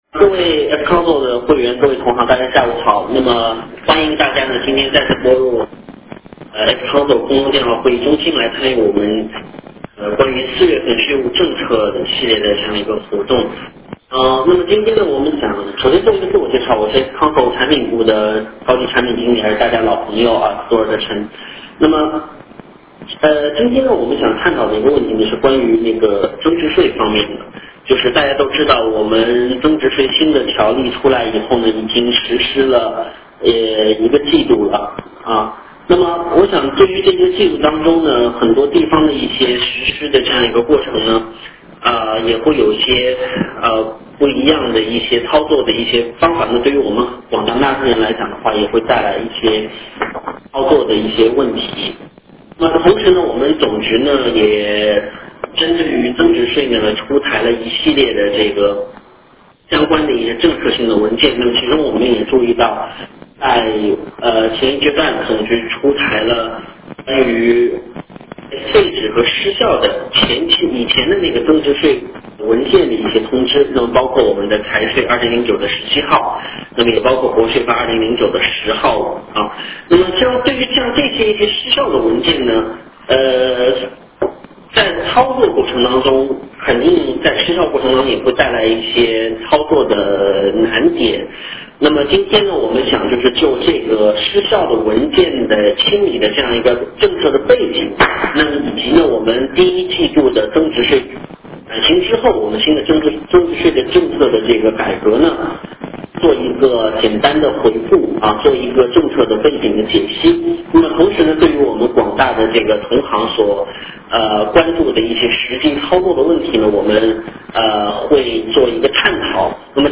形式： 电话会议 特邀嘉宾： 国家税务总局货劳司增值税处副处长